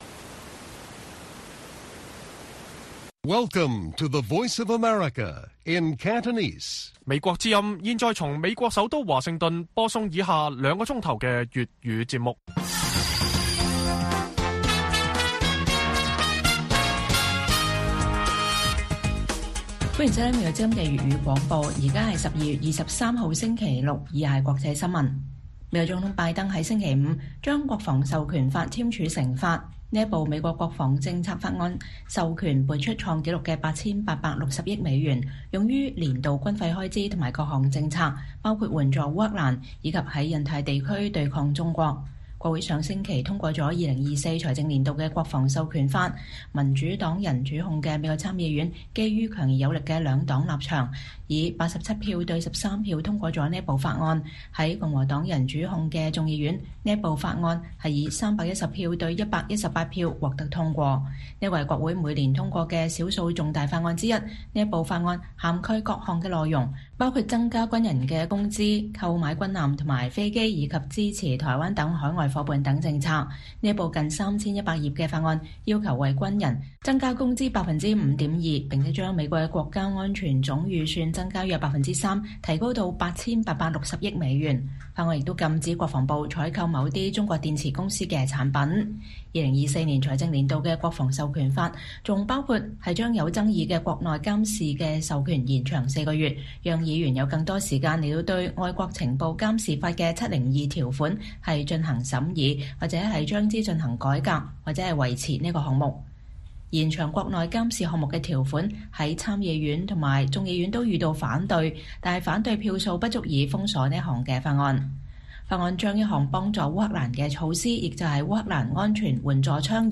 粵語新聞 晚上9-10點: 拜登簽署創紀錄的8,860億美元《國防授權法》，含抗衡中國及支持台灣等內容